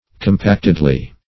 compactedly - definition of compactedly - synonyms, pronunciation, spelling from Free Dictionary Search Result for " compactedly" : The Collaborative International Dictionary of English v.0.48: Compactedly \Com*pact"ed*ly\, adv. In a compact manner.